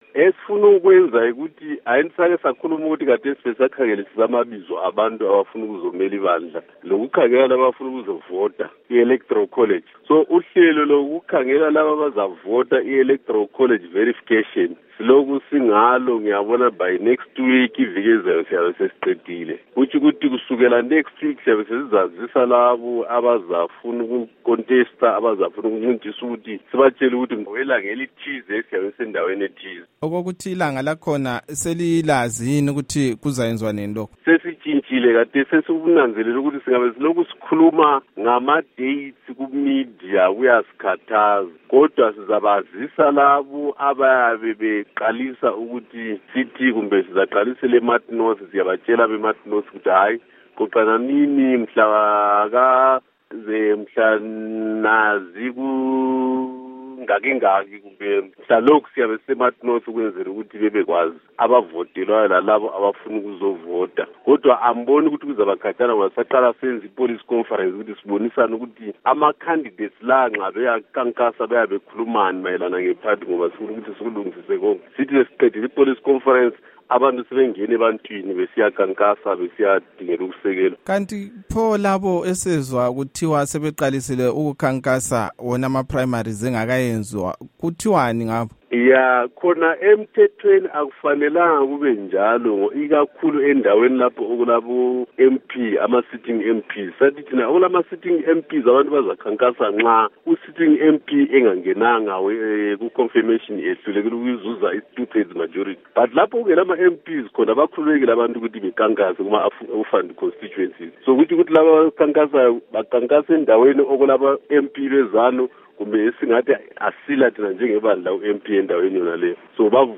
Ingxoxo loMnu.Joel Gabhuza